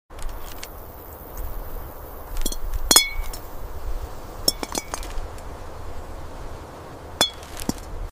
ASMR glass garden vegetables, Tomato